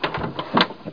dor_open.mp3